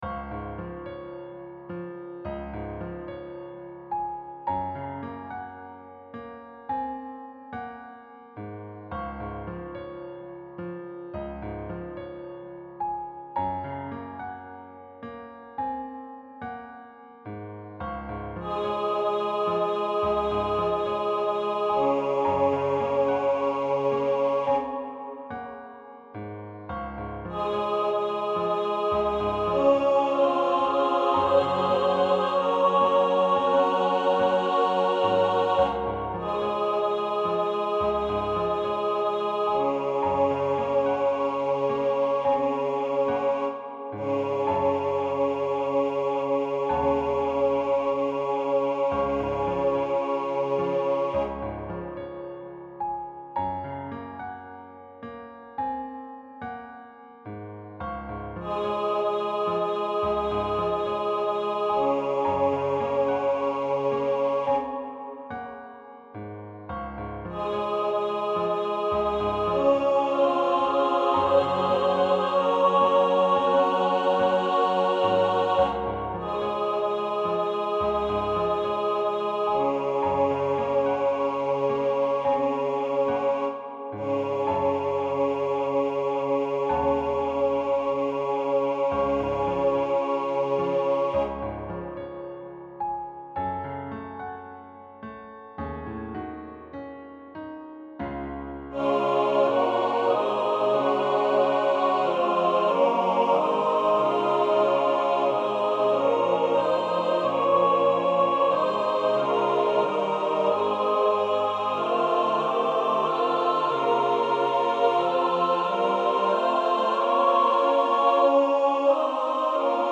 • Music Type: Choral
• Voicing: SATB
• Accompaniment: Piano